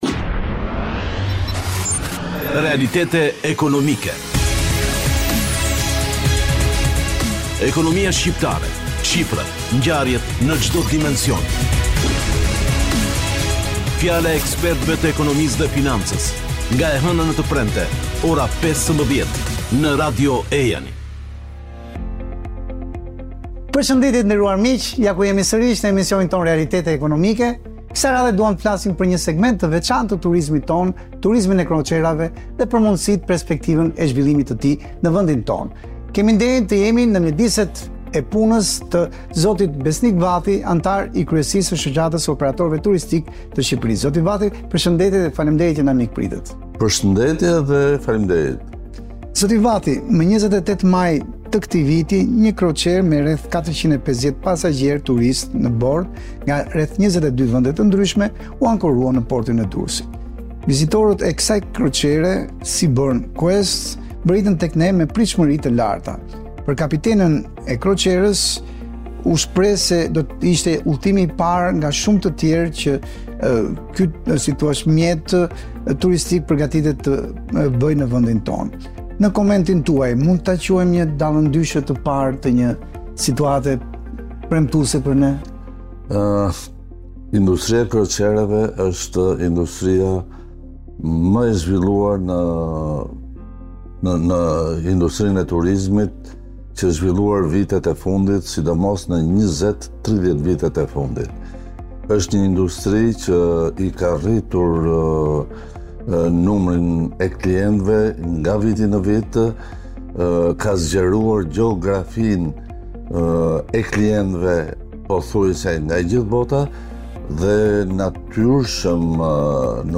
flet për këtë lloj turizmi në intervistë për Grupin Mediatik të Kinës (CMG)